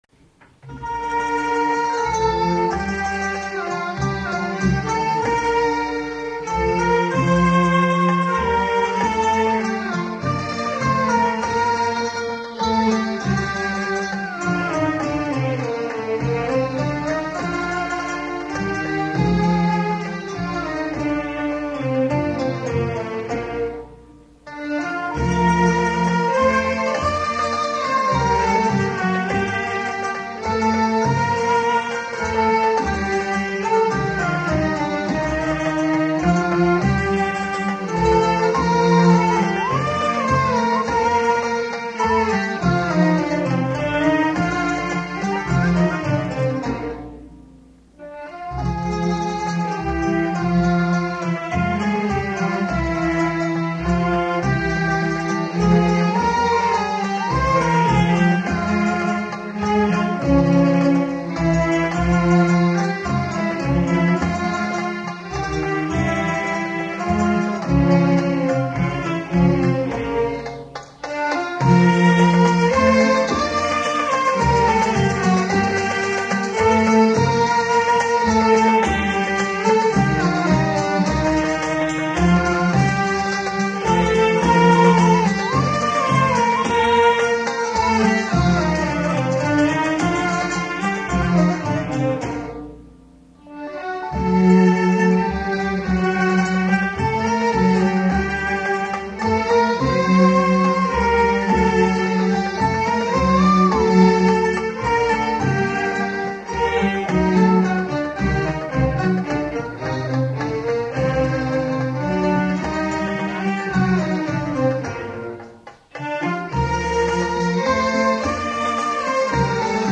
1st Radio Concert